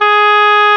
WND OBOE G#4.wav